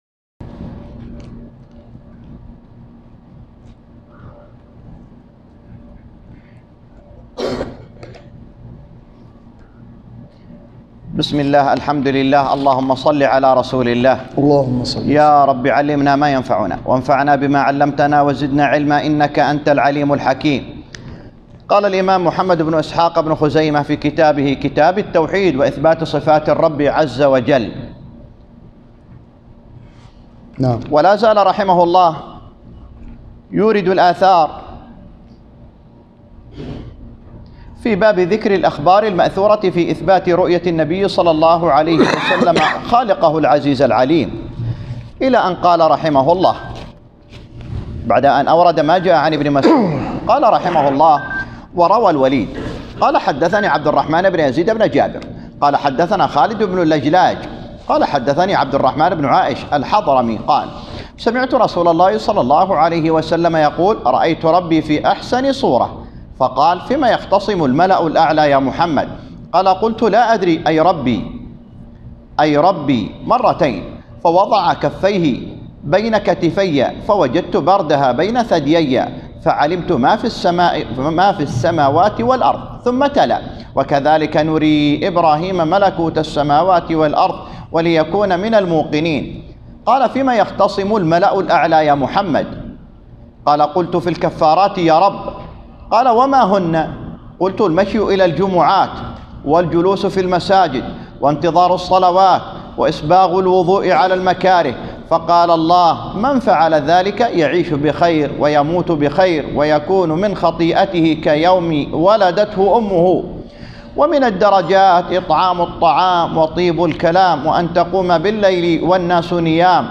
تسجيل لدروس شرح كتاب التوحيد وإثبات صفات الرب عز وجل _ الجزء الثاني (الشرح الجديد) في جامع الخير في ابوعريش
الدرس الثامن و الخمسون